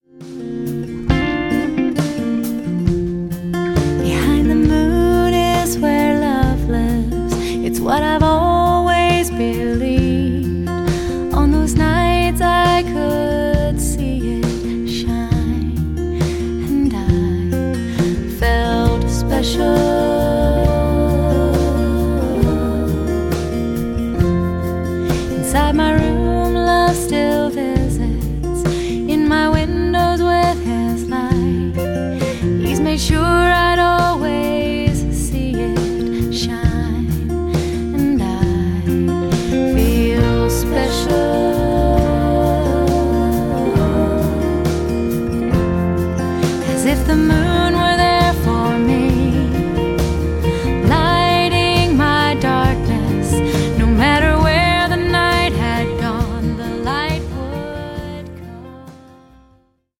Verpackt in leichtfüßige Popmusik mit Celtic-Folk-Einflüssen